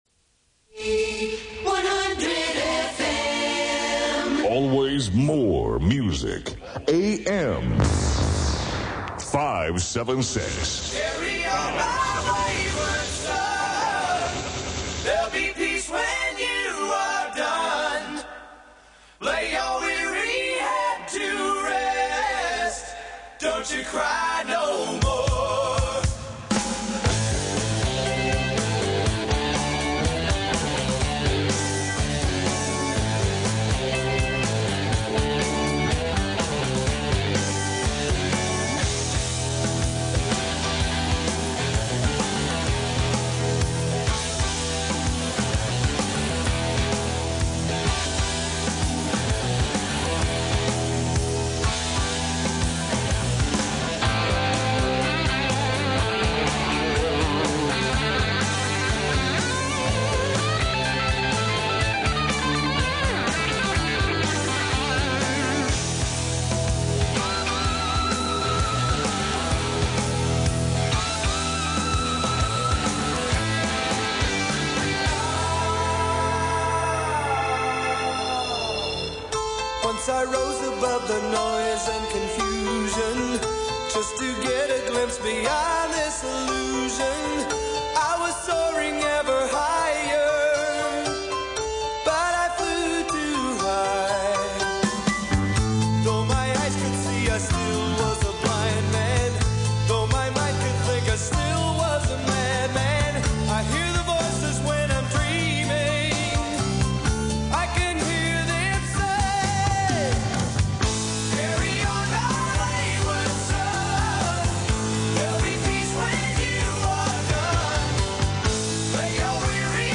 I highly recommend spending a few minutes listening to some classic radio from when I was in Taiwan deejaying on the radio ().
ICRT DJ_1.mp3